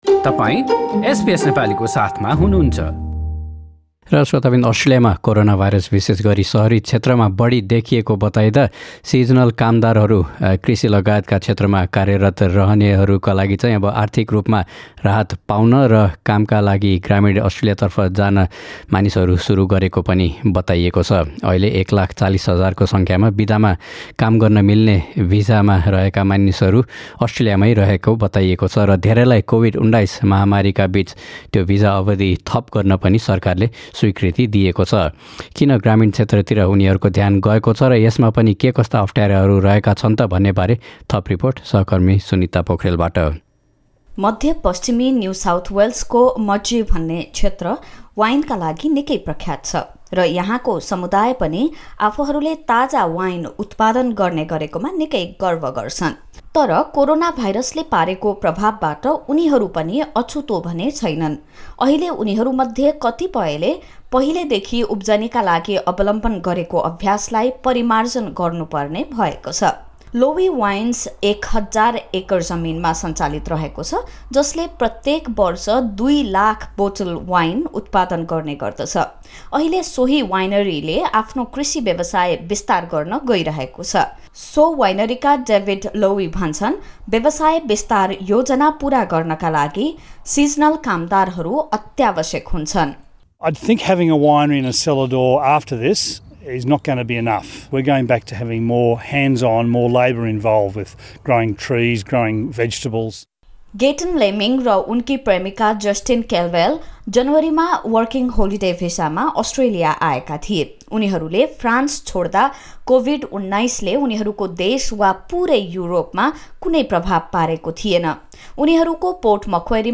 यसबारे थप रिपोर्ट सुन्न माथि रहेको मिडिया प्लेयरमा क्लिक गर्नुहोस्।